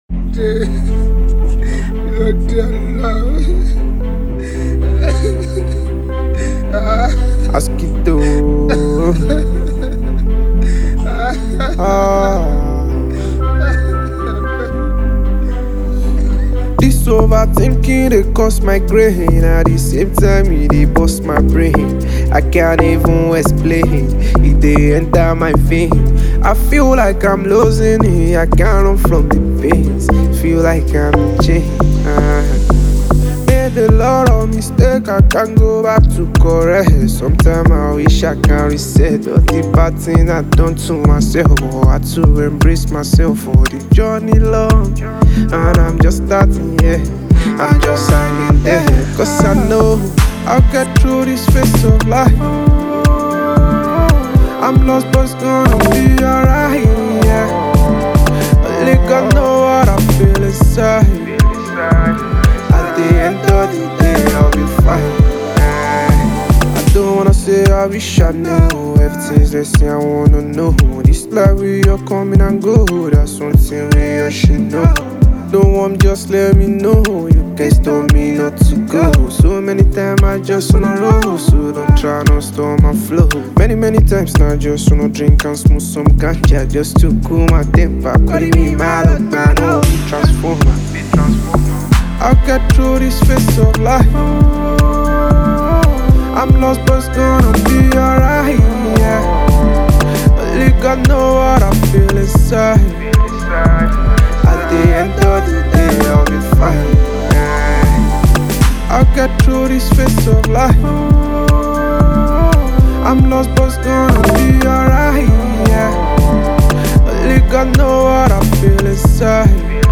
deeply private and emotional single